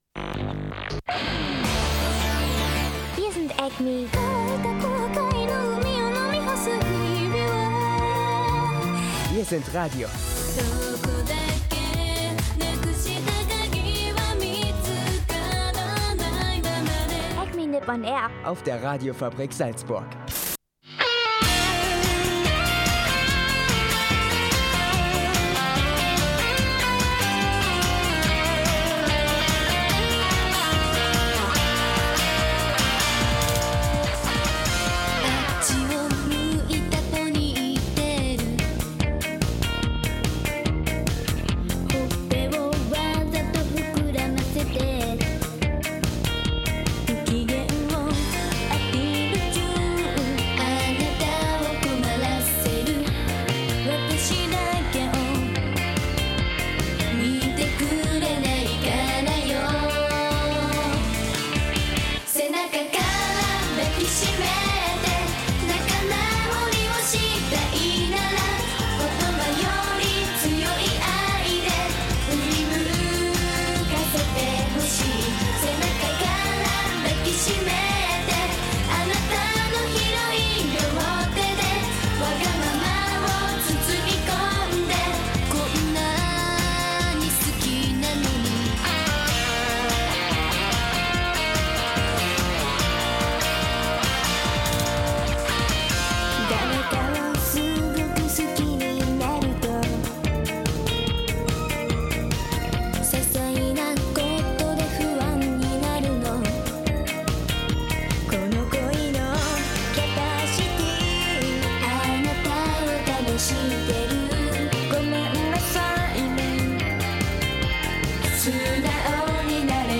In der 98. Folge von Acme.Nipp-on-AiR gehts diese Woche vor allem musikalisch zu, mit Musiktiteln, die noch nie bei Acme.Nipp-on-AiR liefen. Und mit Animetipp (Shirobako) und News und Anime-Gossip natürlich.